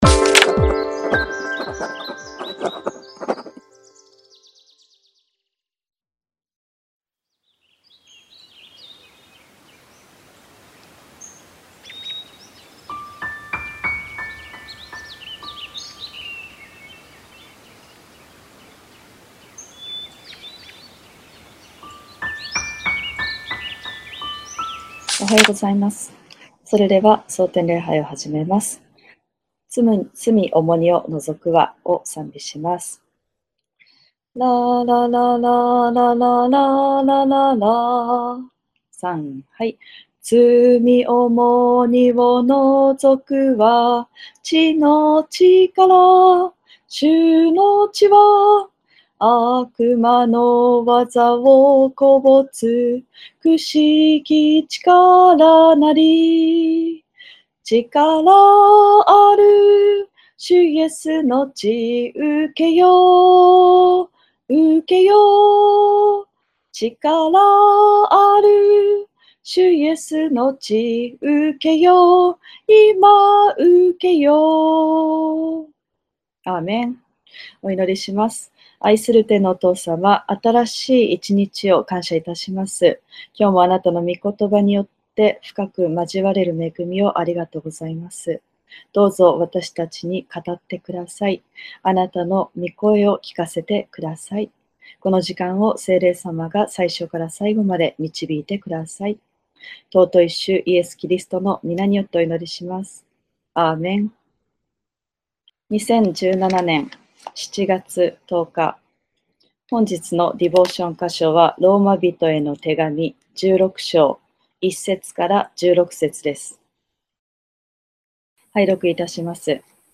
※映像と音声が一部乱れている部分がございます。